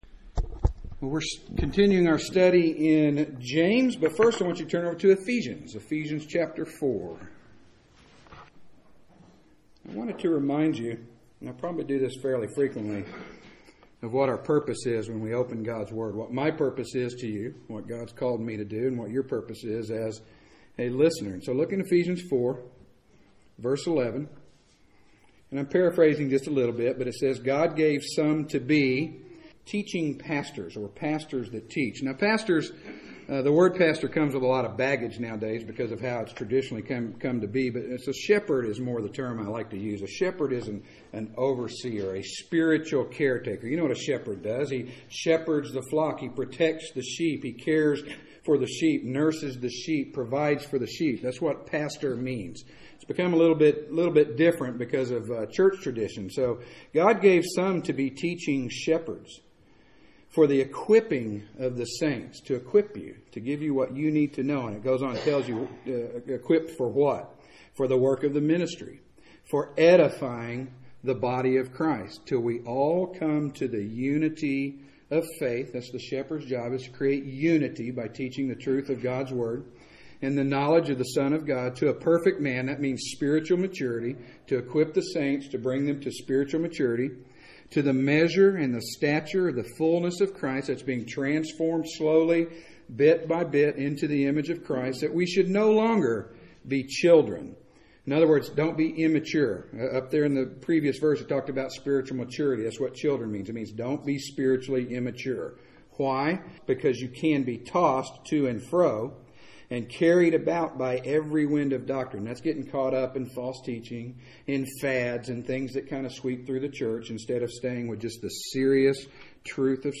Preached at Straightway Bible Church on April 7th, 2013.